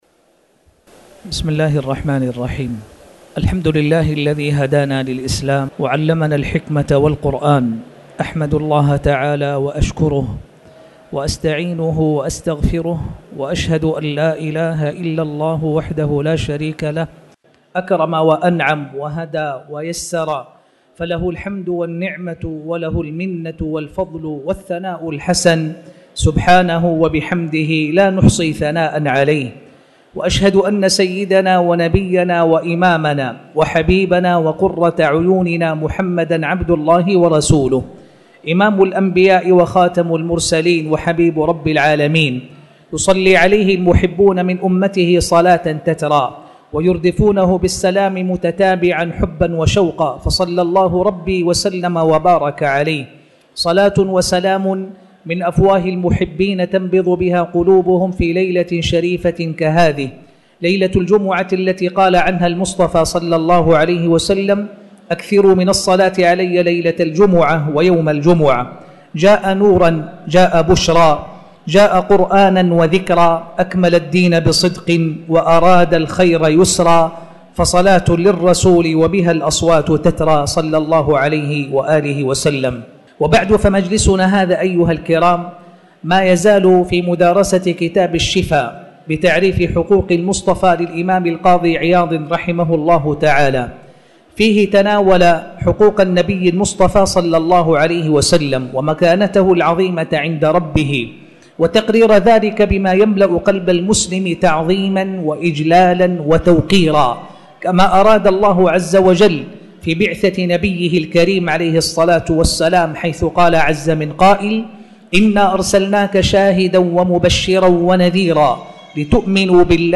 تاريخ النشر ١ جمادى الأولى ١٤٣٩ هـ المكان: المسجد الحرام الشيخ